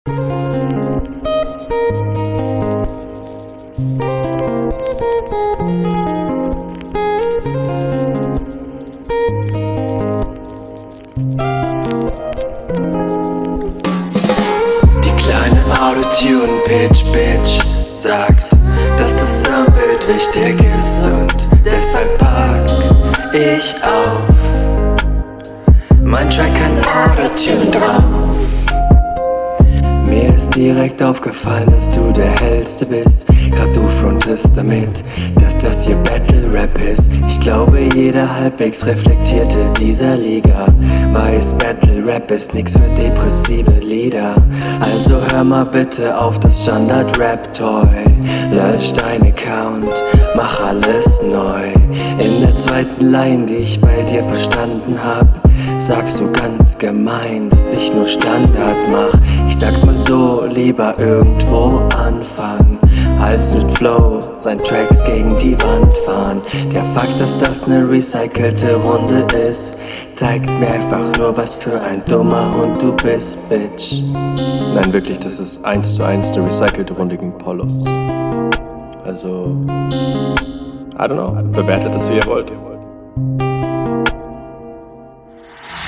Flow: Klingt viel interessanter auf dem Beat.